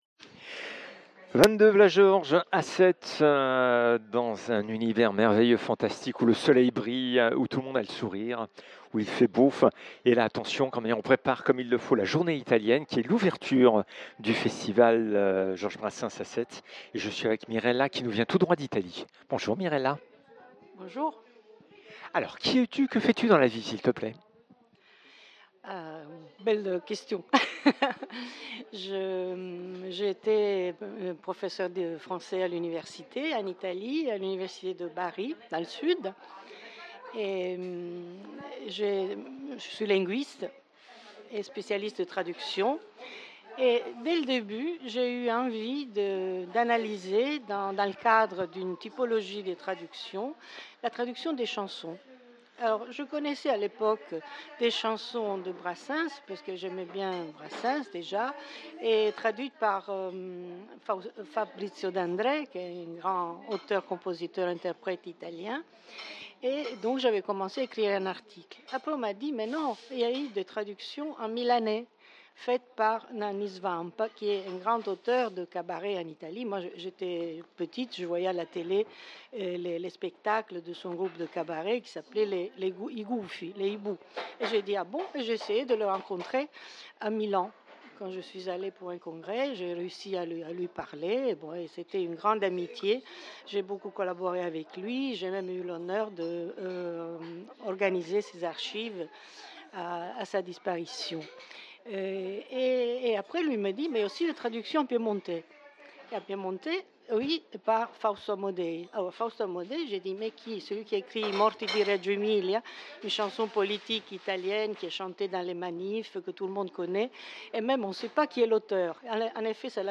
causerie sur Brassens et l'Italie